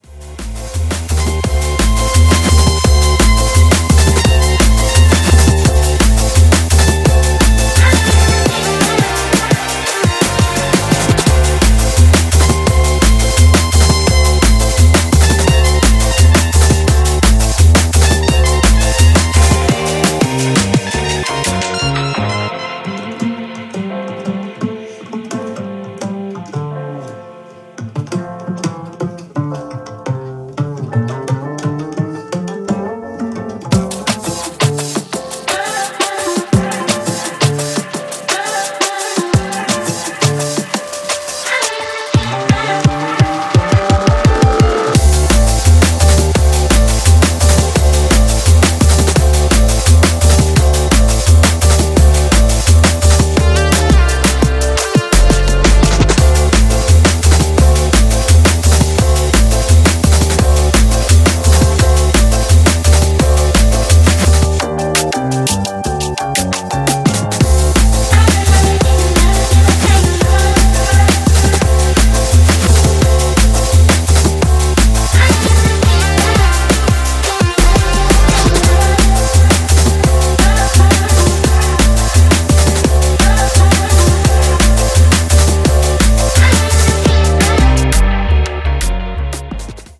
Styl: Drum'n'bass, Jungle/Ragga Jungle